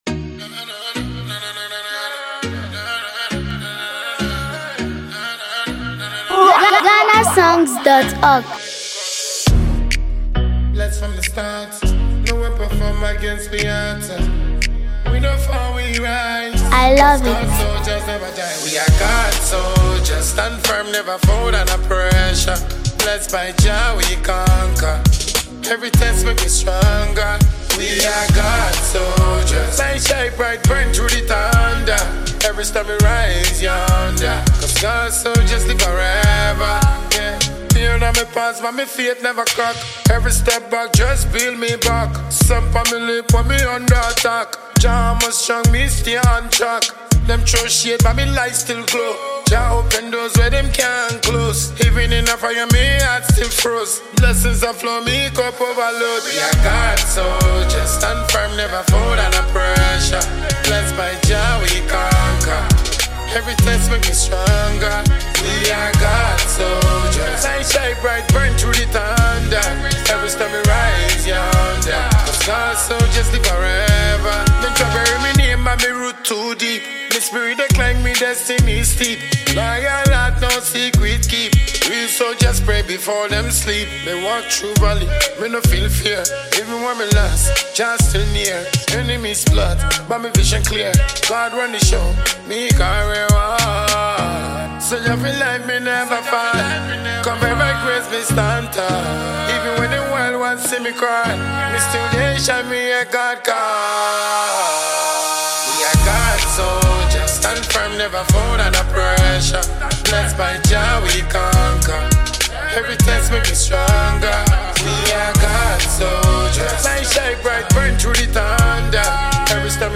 Ghanaian dancehall